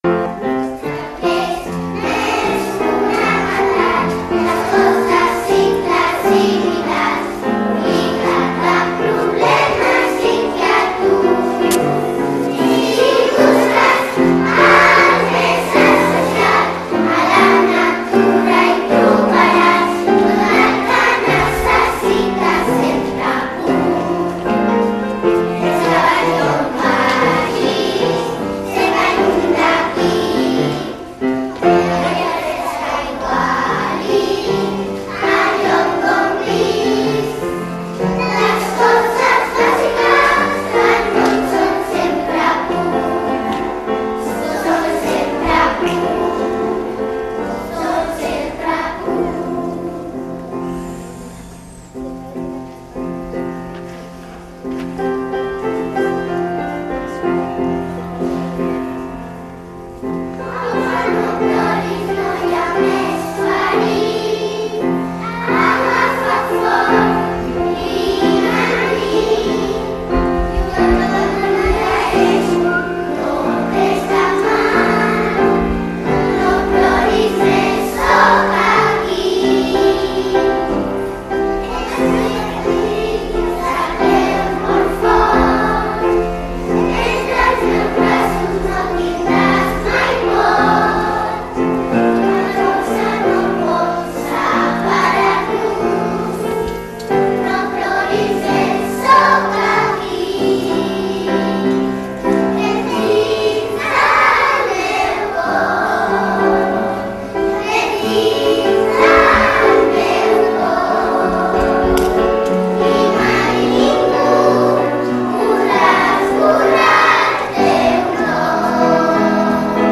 Trobada de Corals
Trobada 2011 Clica el + de l’audio i podràs escoltar cóm canten trobada_corals_2011 Pianista
trobada_corals_20111.mp3